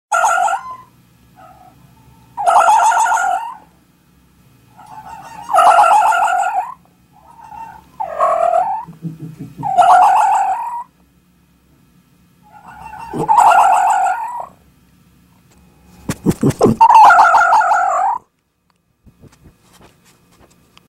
На этой странице собраны разнообразные звуки лисы: от реалистичных рычаний и тявканий до весёлых детских песенок.
Голос лисы